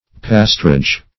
Search Result for " pastorage" : The Collaborative International Dictionary of English v.0.48: Pastorage \Pas"tor*age\, n. The office, jurisdiction, or duty, of a pastor; pastorate.